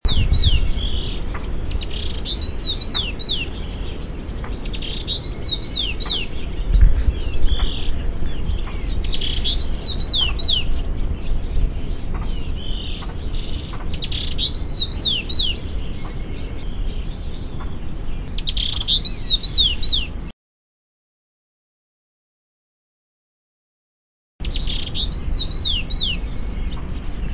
粉紅鸚嘴 Paradoxornis webbianus bulomachus
高雄市 左營區 半屏山
錄音環境 人工林
鳥叫
Sennheiser 型號 ME 67